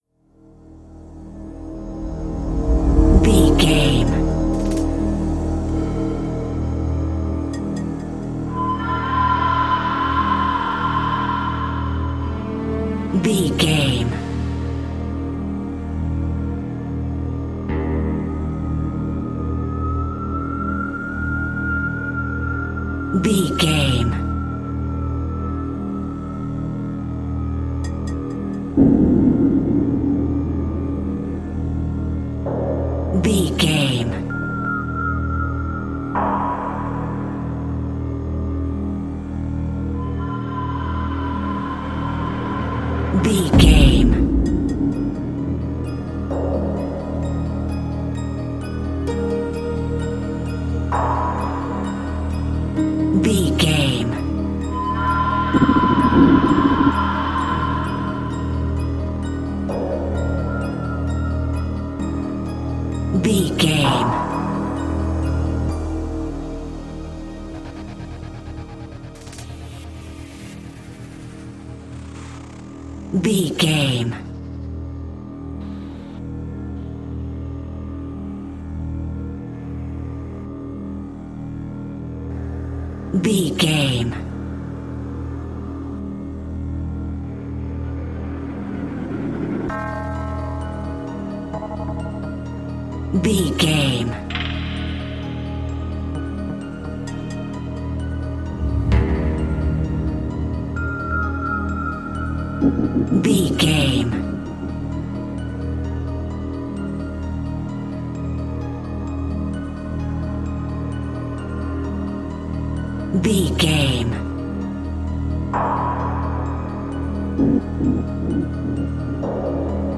Aeolian/Minor
E♭
synthesiser
percussion
tension
ominous
suspense
mysterious
haunting
creepy
spooky